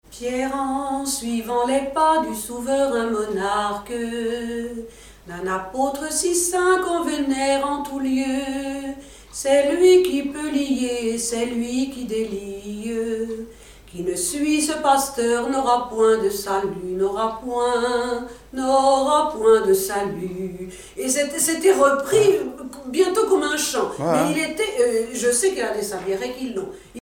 circonstance : cantique
Pièce musicale inédite